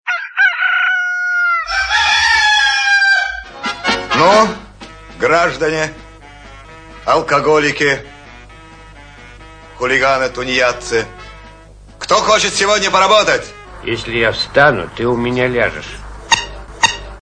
bydilnik.mp3